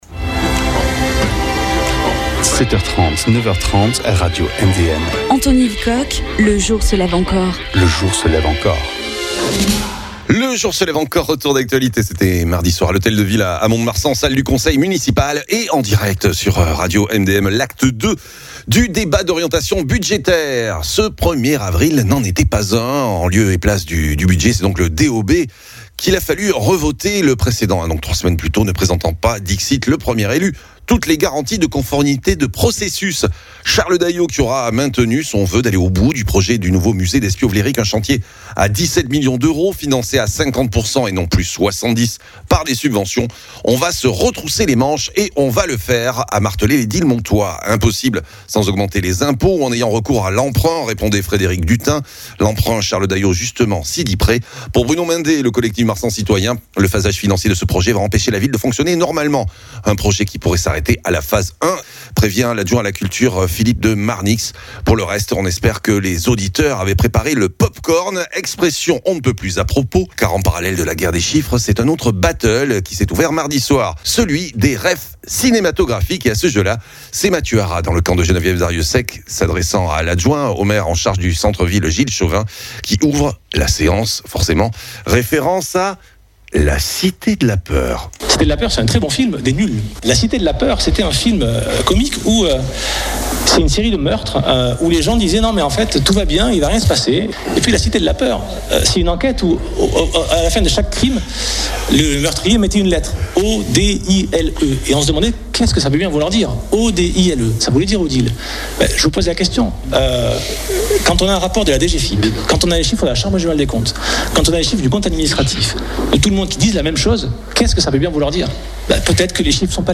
De « La Cité de la Peur » à « La Vérité si je Mens » , le Débat d’Orientation Budgétaire (Part II) mardi soir a donné lieu à une joute de chiffres mais aussi un battle de références cinématographiques lors d’une séance qui aurait pu aussi s’appeler  » Une Nuit au Musée  » , le projet du nouveau musée Despiau-Wlérick à 17 millions d’euros soutenu par le Maire Charles Dayot ayant occupé une bonne partie des débats….👇